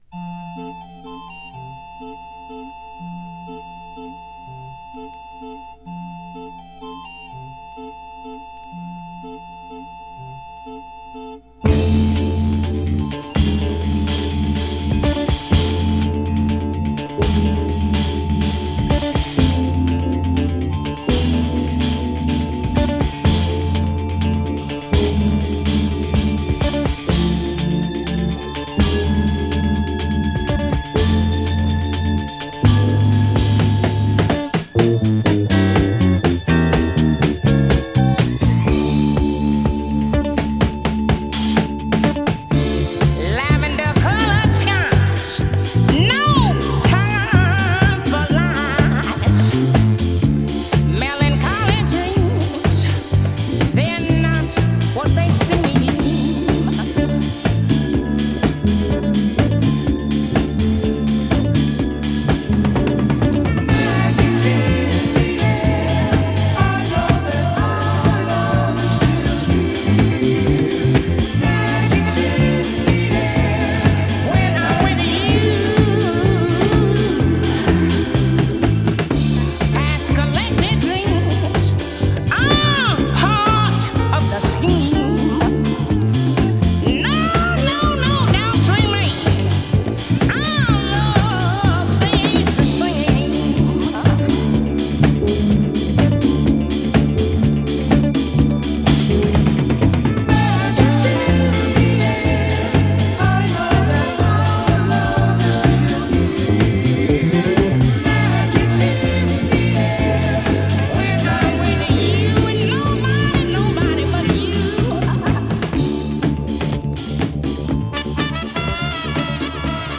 DISCO
JAZZ BOOGIE〜DISCO BREAK !!